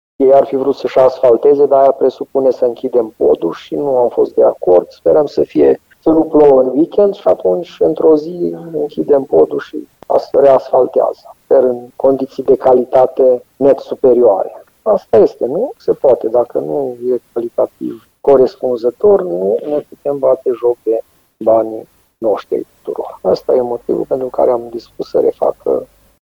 Primarul Aradului precizează că stratul de suprafaţă al asfaltului este vălurit şi trebuie refăcut. Ca urmare, reîncep lucrările, frezarea fiind primul pas făcut deja de echipele de muncitori, spune Călin Bibarț.